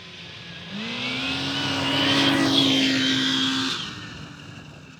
Subjective Noise Event Audio File (WAV)